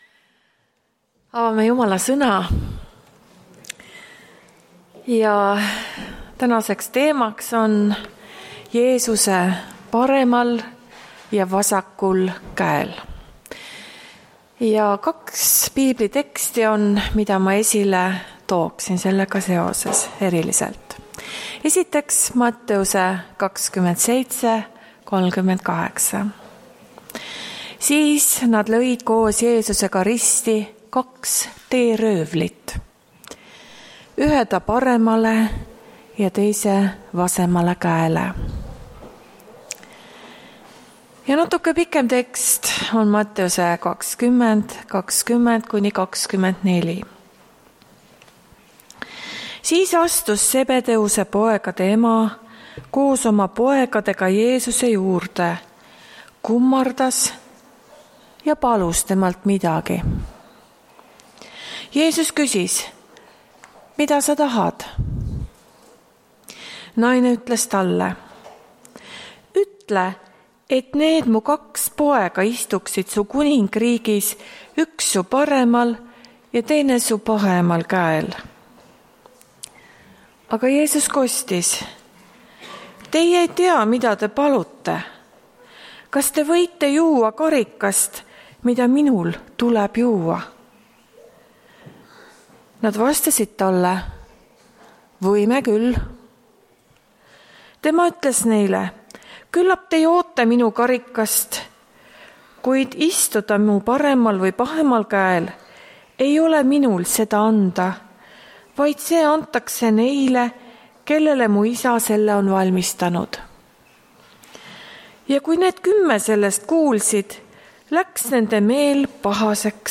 Tartu adventkoguduse 26.06.2021 hommikuse teenistuse jutluse helisalvestis.